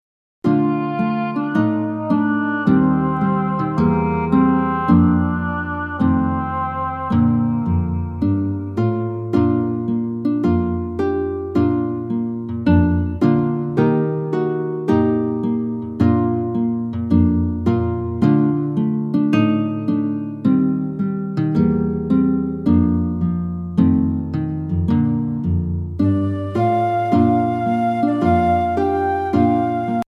Listen to a sample of the instrumental track.